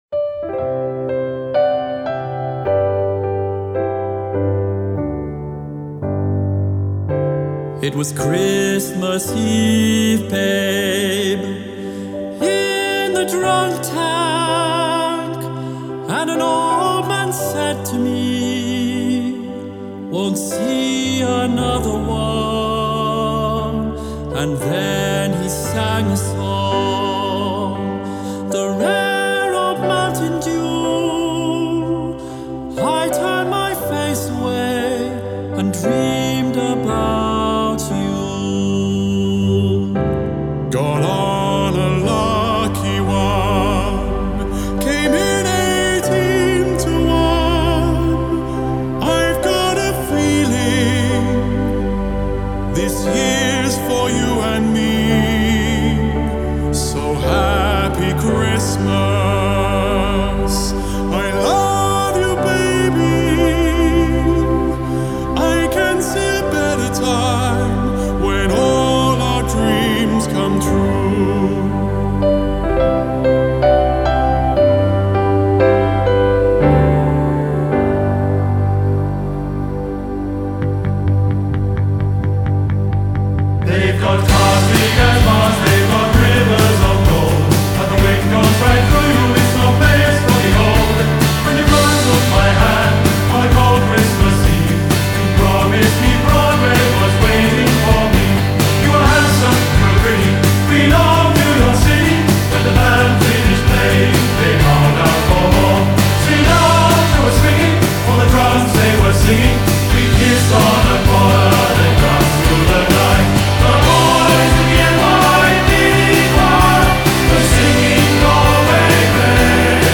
Genre: Pop, Chants